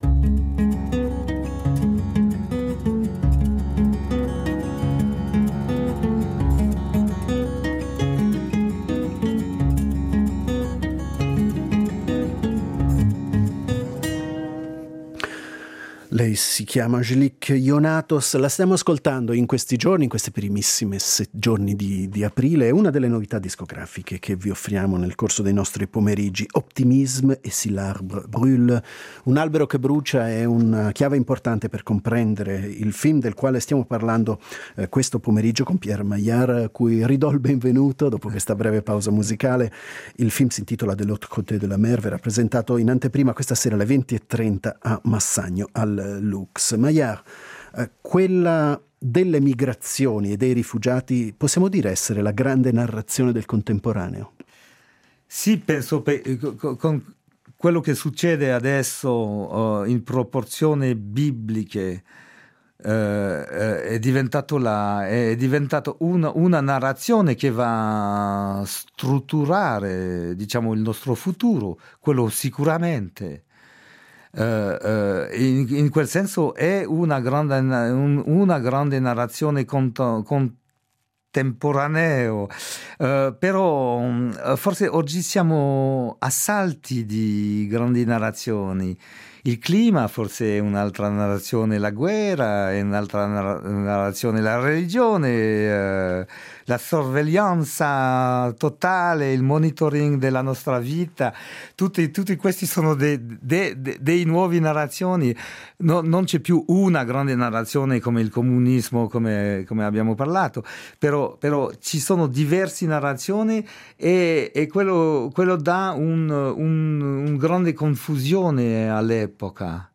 Abbiamo intervistato il regista (seconda parte)